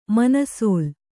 ♪ manasōl